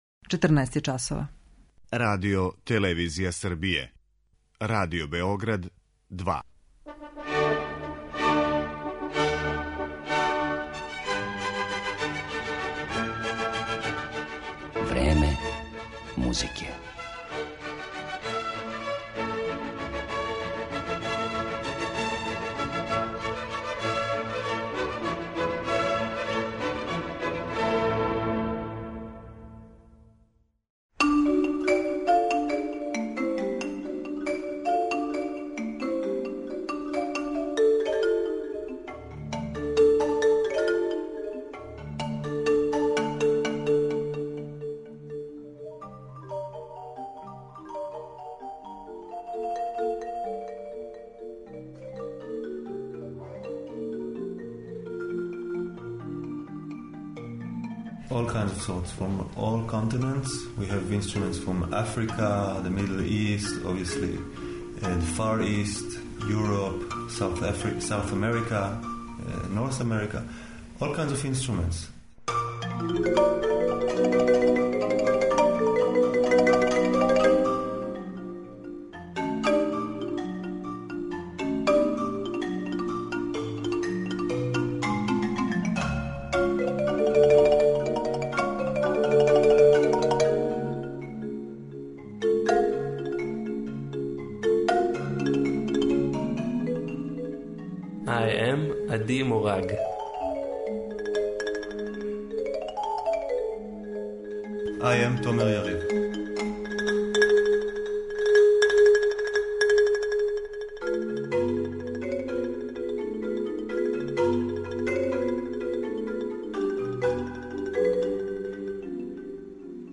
Овом изузетном ансамблу посвећено је данашње Време музике, у коме ћемо емитовати и интервју снимљен са извођачима приликом једног од њихових гостовања у Београду.